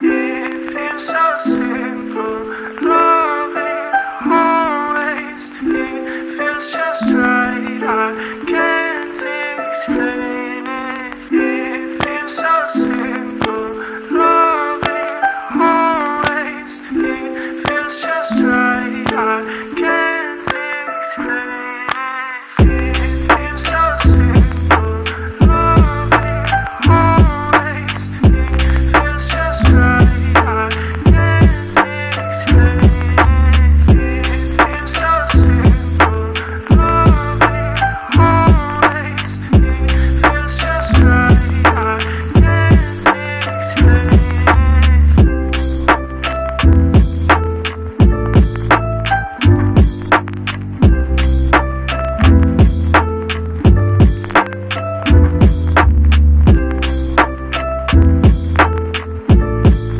Chill lofi for your next late night library session.